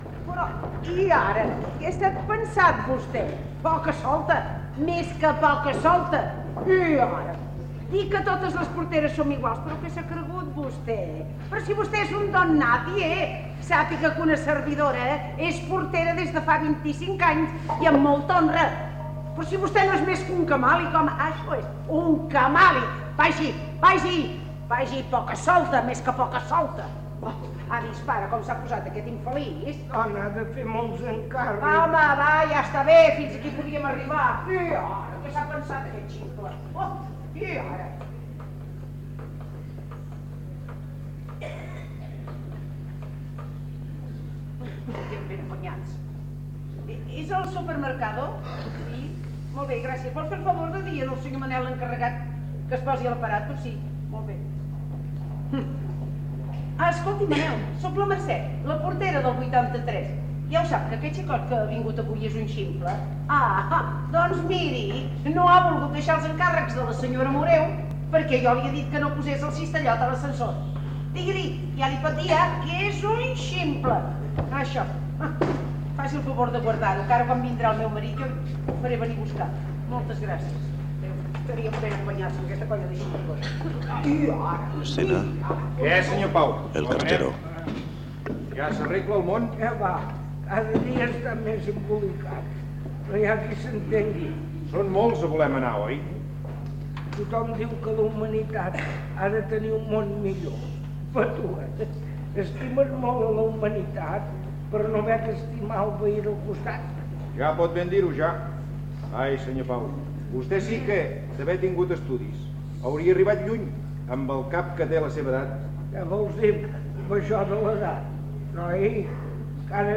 5800d90609b713ed64d0cfaa5ae781851d32067f.mp3 Títol Ràdio Sabadell EAJ-20 - Radioteatre Emissora Ràdio Sabadell EAJ-20 Titularitat Privada local Nom programa Radioteatre Descripció Transmissió del primer acte de l'obra de teatre "Fang", d'Eduard Criado, des del Teatre Romea de Barcelona.